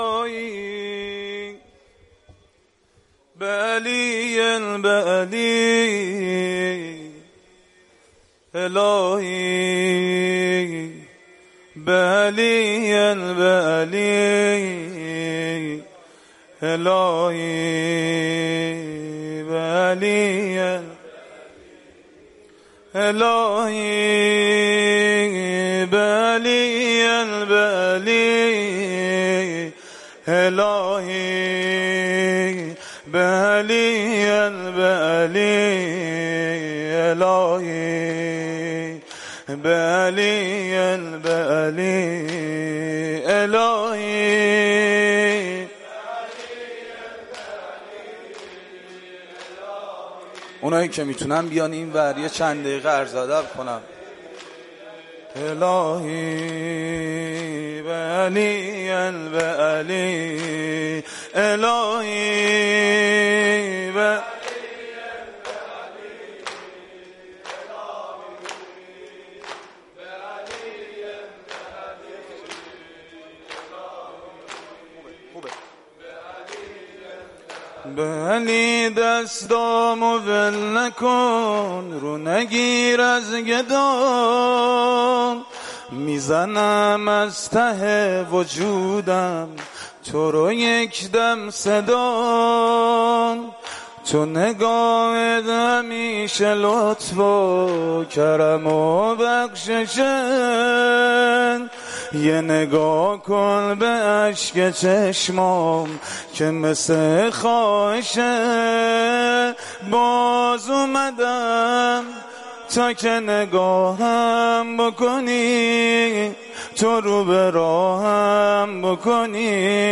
سینه زنی شب هشتم مراسم ماه رمضان97